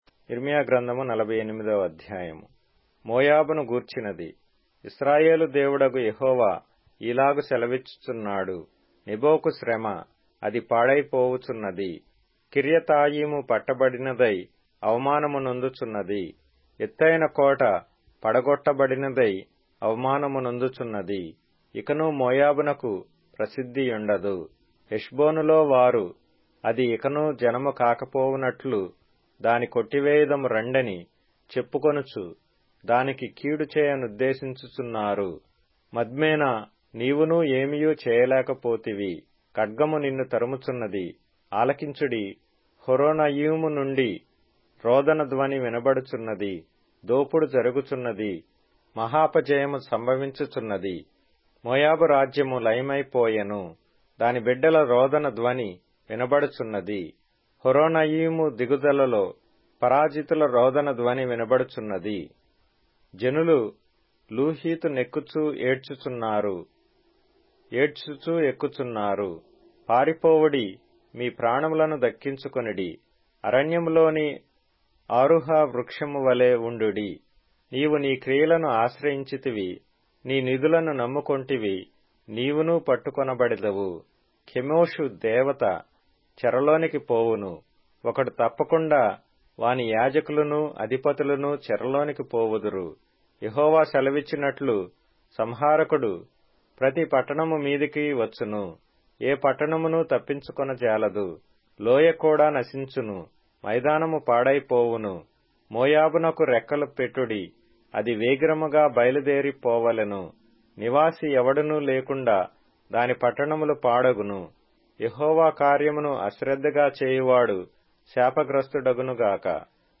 Telugu Audio Bible - Jeremiah 13 in Ervpa bible version